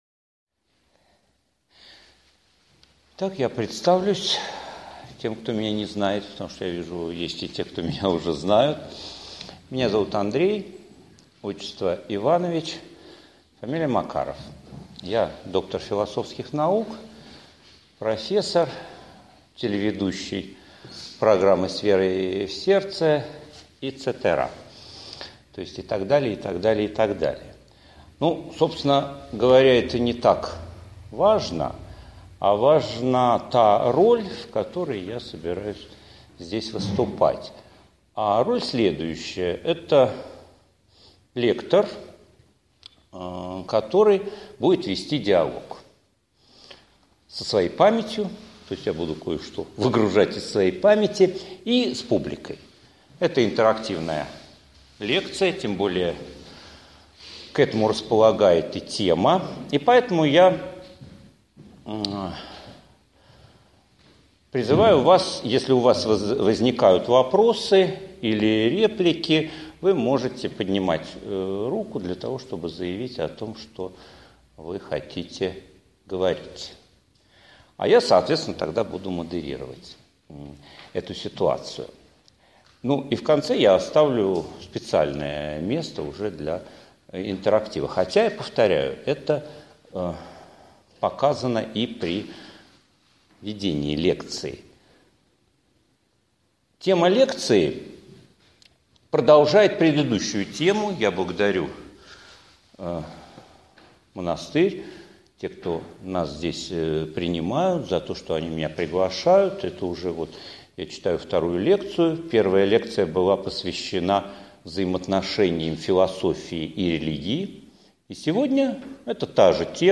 Аудиокнига Авраамизм и диалогизм: христианская и еврейская философия диалога ХХ века | Библиотека аудиокниг